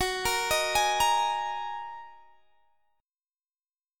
Gb6add9 Chord
Listen to Gb6add9 strummed